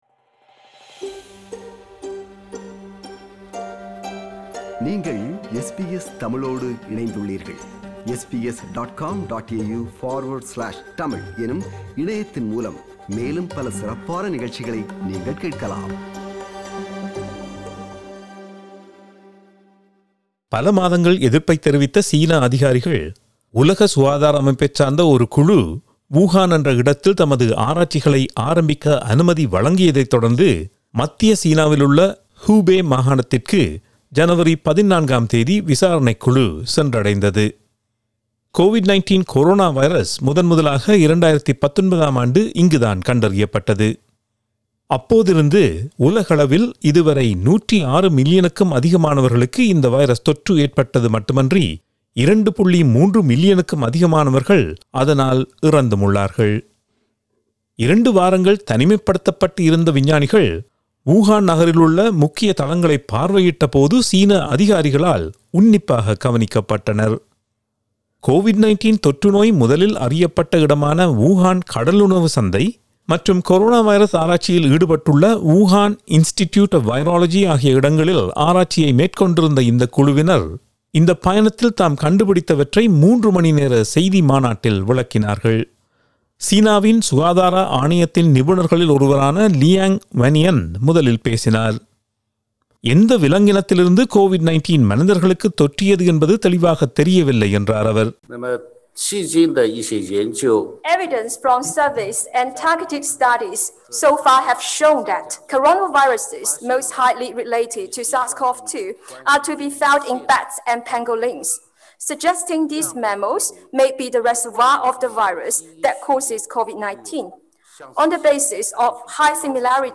reports in Tamil